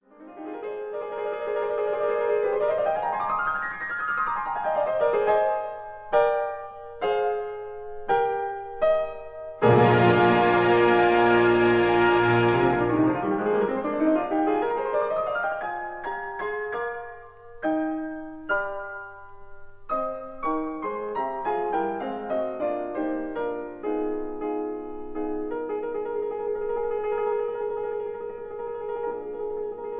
1959 stereo recording